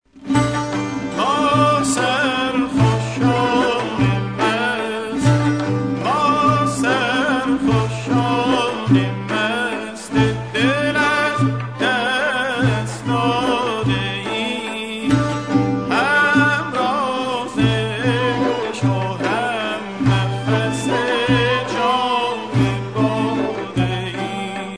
伊朗古典音樂１